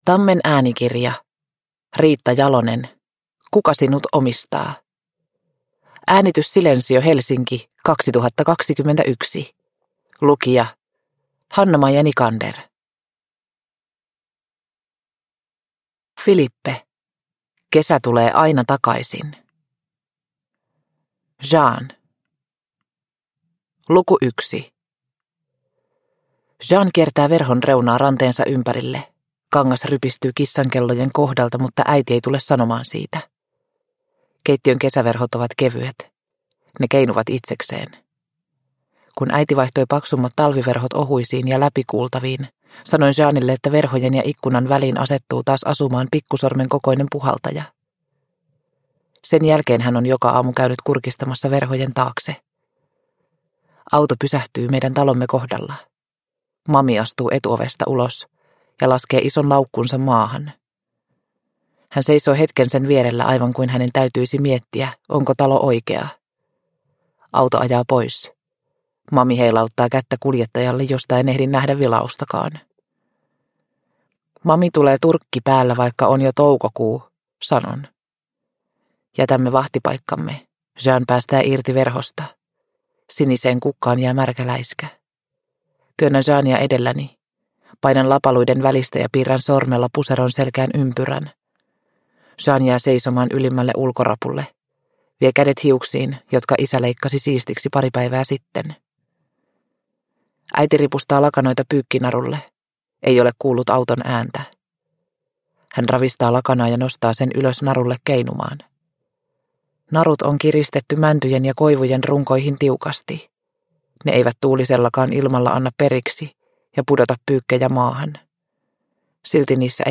Kuka sinut omistaa – Ljudbok – Laddas ner